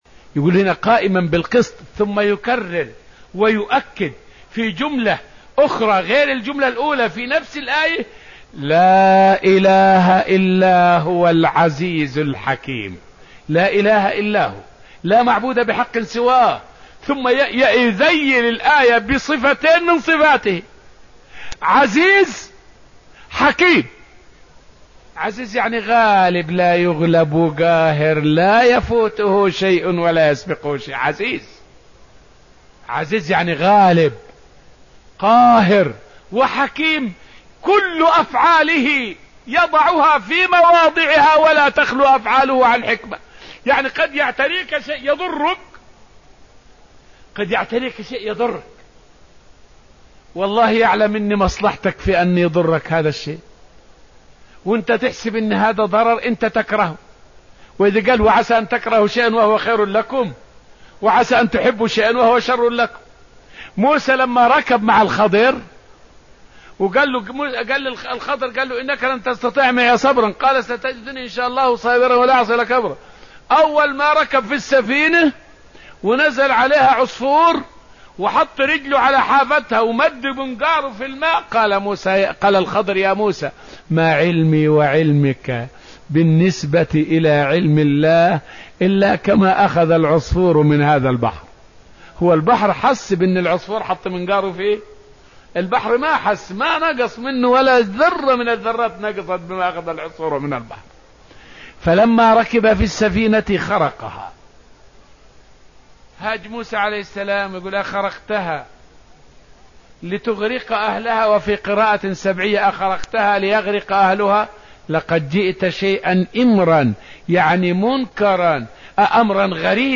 فائدة من الدرس السادس من دروس تفسير سورة آل عمران والتي ألقيت في المسجد النبوي الشريف حول تفسير قوله تعالى {لا إله إلا هو العزيز الحكيم}.